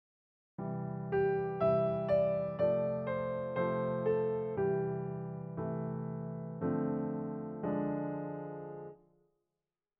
Below are two examples of the same melody, one with notated chords, and the other with just chord symbols.
As you can hear, in the first example I’m playing the chords exactly as written, but in the second example I’m generating not only the chords but also their rhythms based on my ability to interpret the chord symbols.